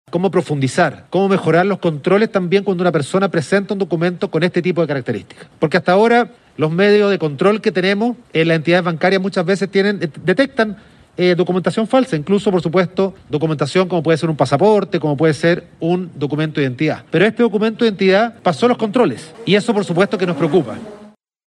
El ministro del Interior Rodrigo Delgado manifestó su preocupación por el nivel de sofisticación de estas bandas y de cómo tratar estos delitos con la banca.